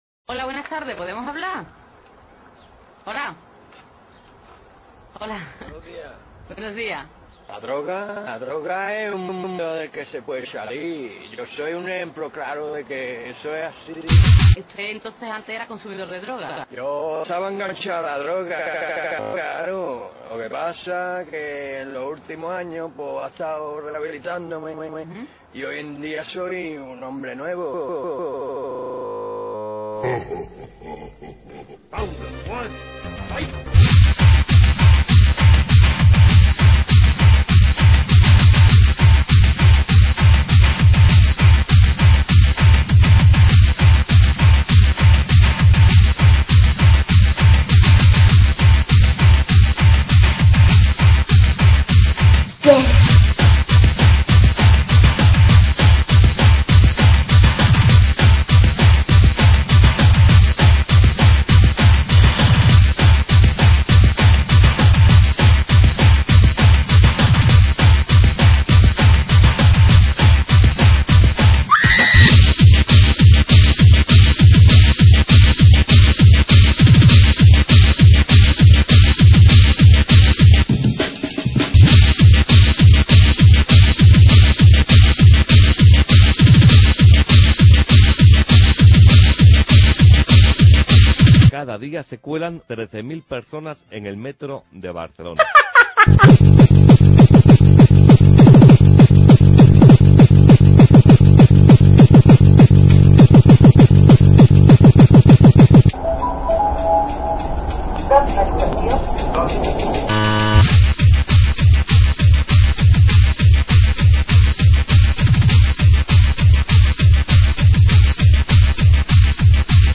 29/10/2011 Etiquetes: Hardtek Descàrregues i reproduccions